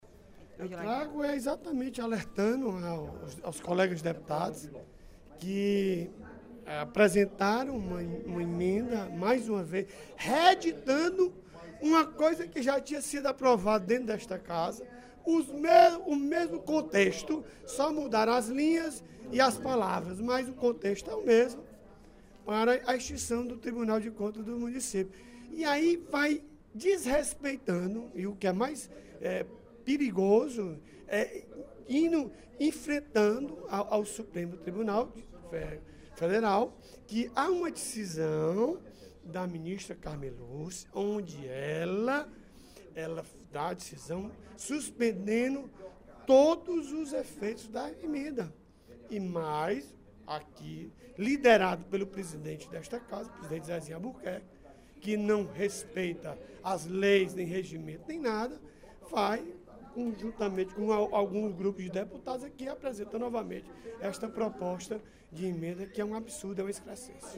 O deputado Odilon Aguiar (PMB) reafirmou, nesta quinta-feira (18/05), durante o primeiro expediente da sessão plenária, as críticas em relação à reedição de proposta de emenda à Constituição (PEC) que trata da extinção do Tribunal de Contas dos Municípios (TCM).